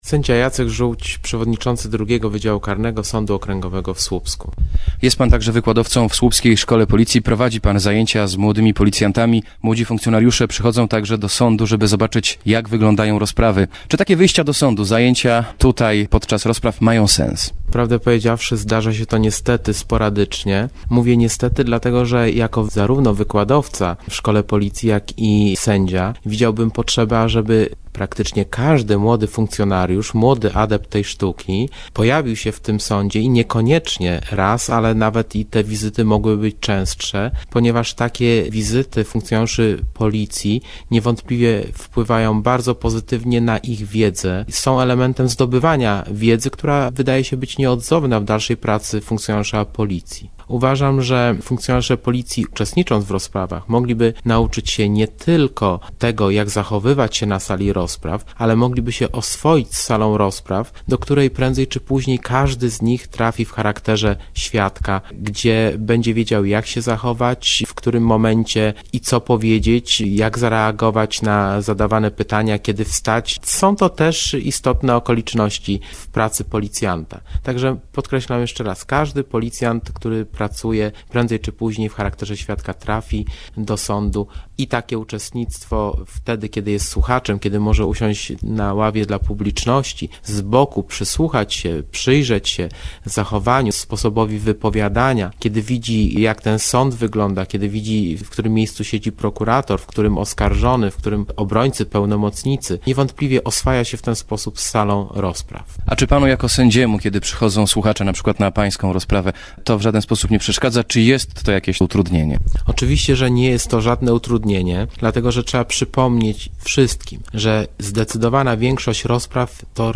Sędzia Sądu Okręgowego - Jacek Żółć